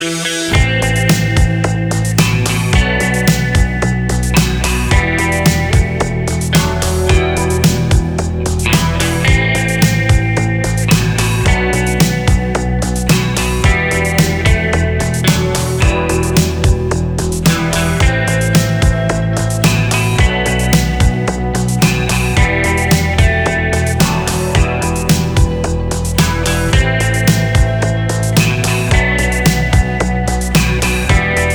• Качество: 320, Stereo
спокойные
без слов
электрогитара
русский рок